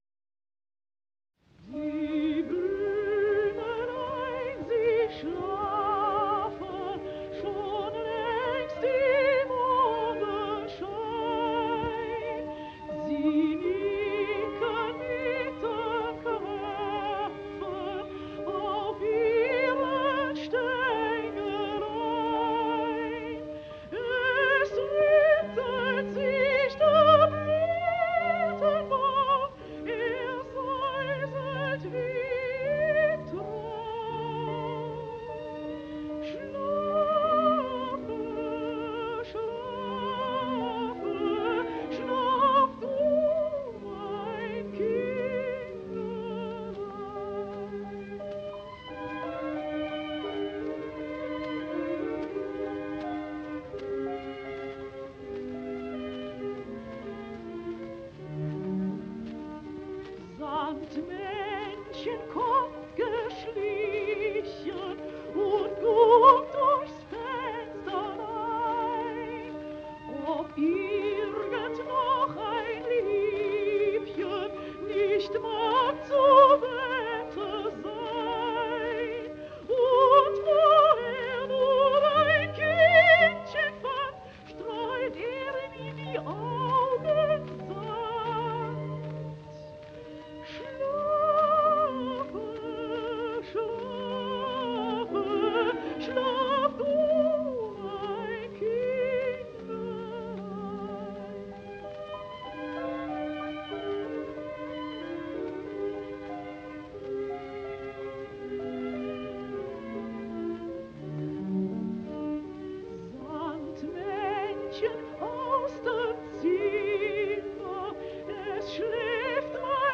Gute-Nacht- und Schlaflied